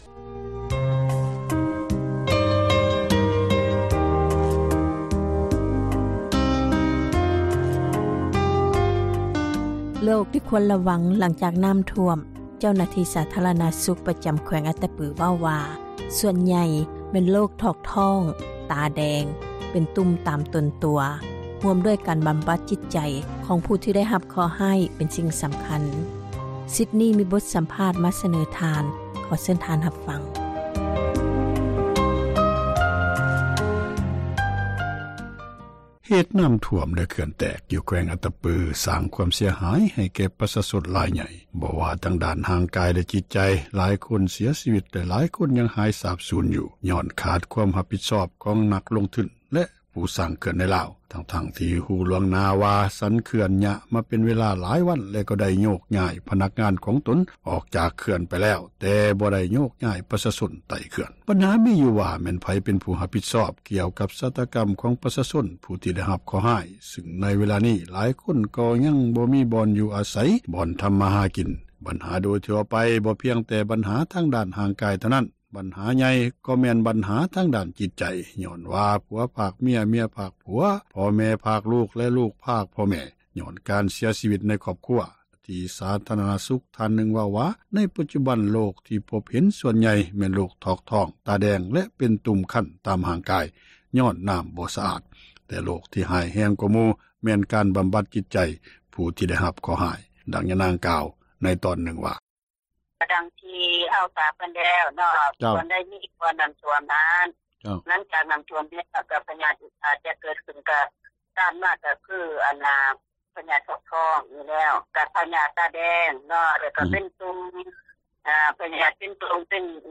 ສໍາພາດ ທ່ານໝໍ ເຣື້ອງ ໂຣຄພັຍ ໃນສູນ ພັກເຊົາ